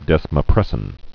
(dĕsmə-prĕsĭn)